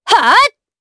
Chrisha-Vox_Attack4_jp.wav